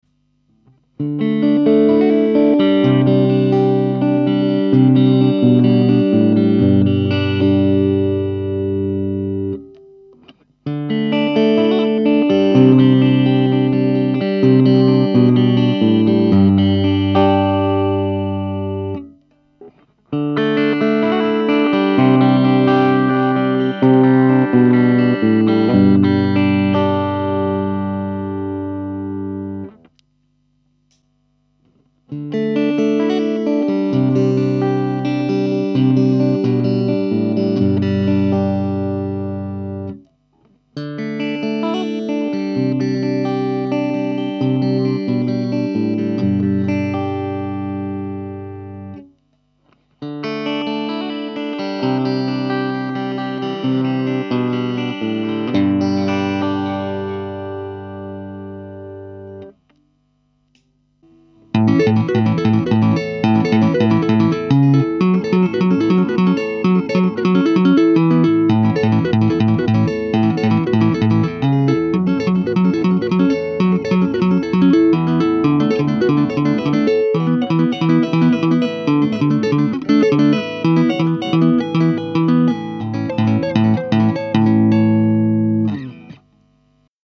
DBZ Barchetta ST-FR Metallic BlueЗвук соответственно мощный, ВЧ немного больше, чем в предыдущем варианте, но опять же чистый звук убог, уклон идет в середину для раскачки перегруза.
1. Чистый звук 2,04 Мб
Neck, N+B, Bridge, плюс тоже самое с отсечкой, фрагмент тэйпинга
clean.mp3